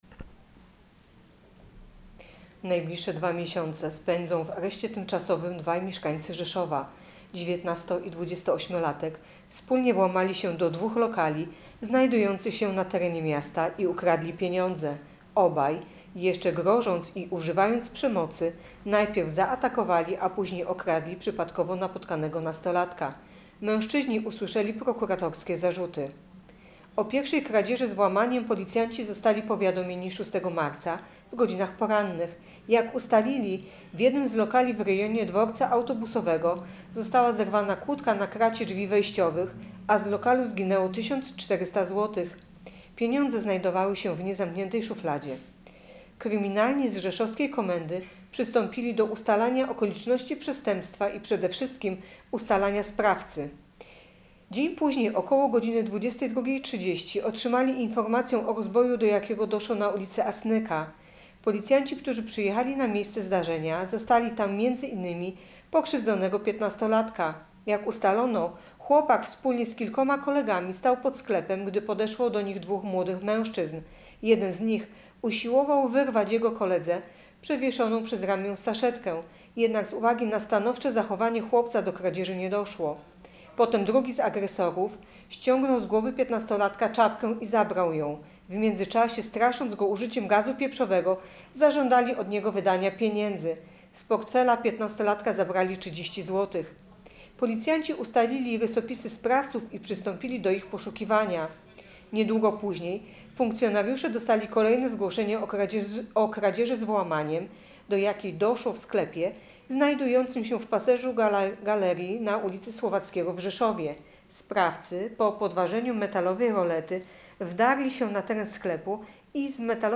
Opis nagrania: Nagranie informacji pt. Policjanci zatrzymali sprawców kradzieży z włamaniem i rozboju.